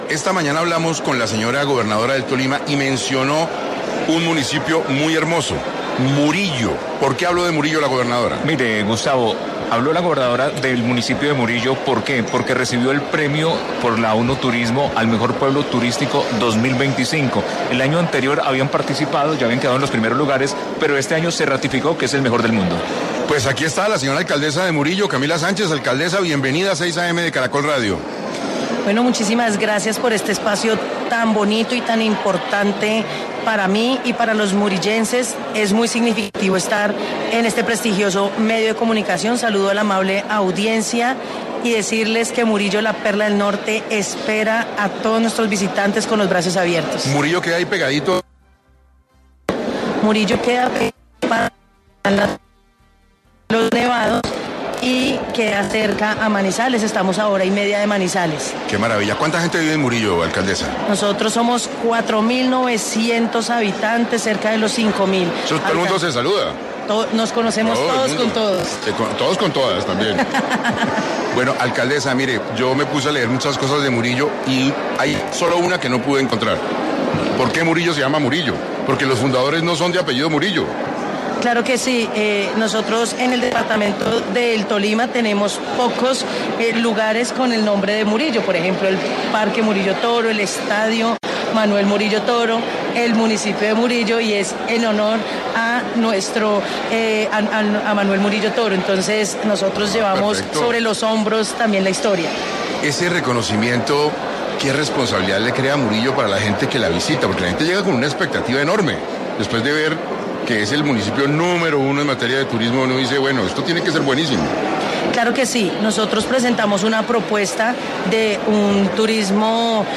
Camila Sánchez, alcaldesa de Murillo, Tolima, pasó por 6AM para hablar de los avances turísticos de este territorio, luego de ser declarado, por la ONU Turismo, como Mejor Pueblo Turístico 2025.
Alrededor de esta coyuntura, la alcaldesa Sánchez Velásquez, pasó por los micrófonos de 6AM para ampliar la información sobre la oferta turística del municipio de Murillo.